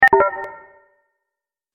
دانلود صدای ربات 59 از ساعد نیوز با لینک مستقیم و کیفیت بالا
جلوه های صوتی